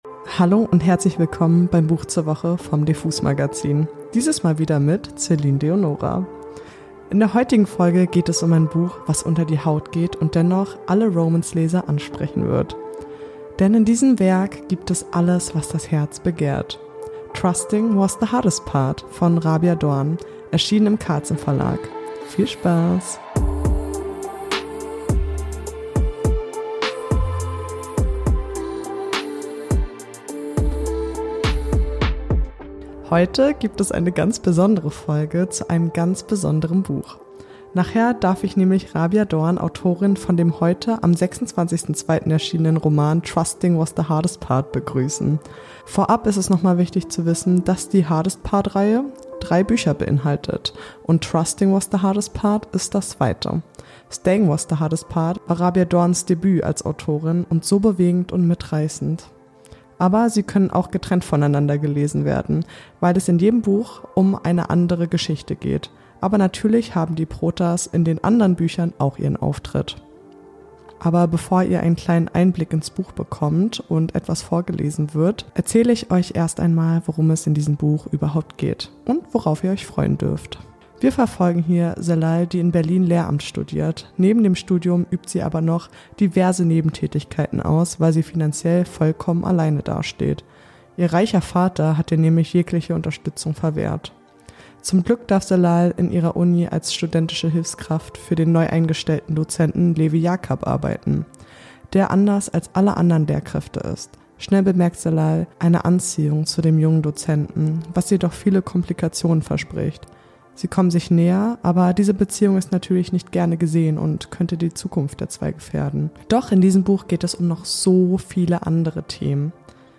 Freut euch außerdem auf eine Szene aus dem Hörbuch, dass euch sofort ins Buch saugen wird.